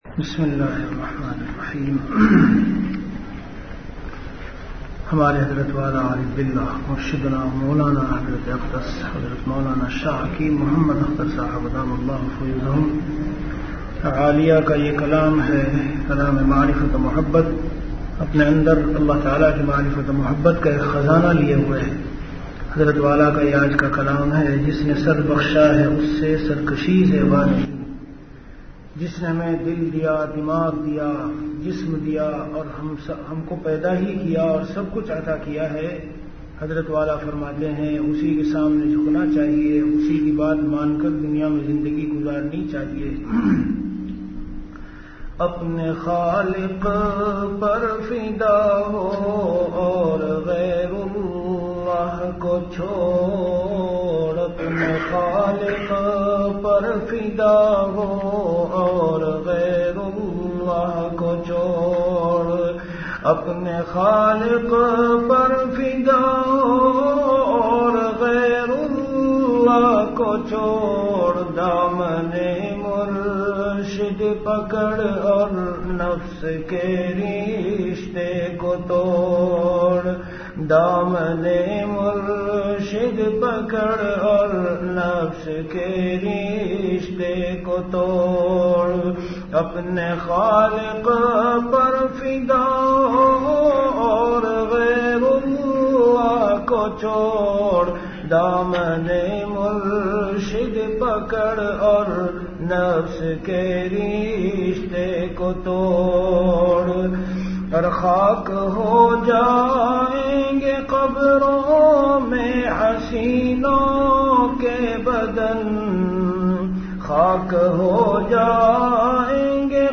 An Islamic audio bayan
Delivered at Khanqah Imdadia Ashrafia.